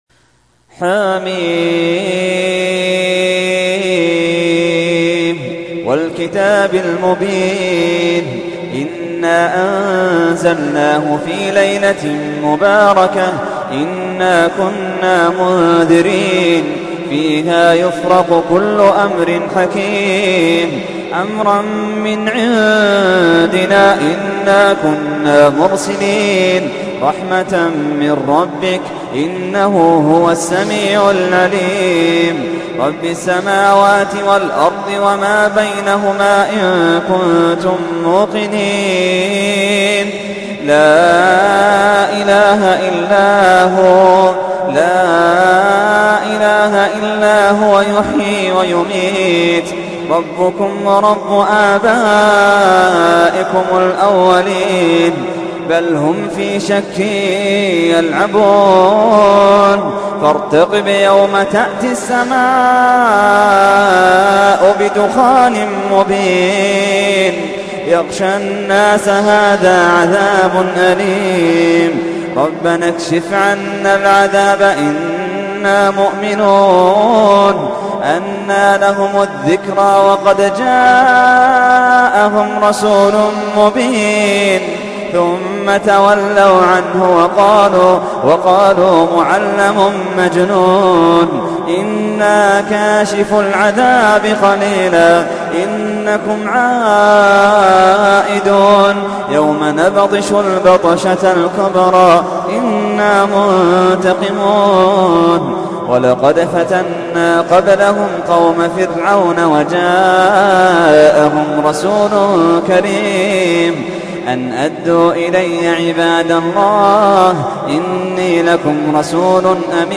تحميل : 44. سورة الدخان / القارئ محمد اللحيدان / القرآن الكريم / موقع يا حسين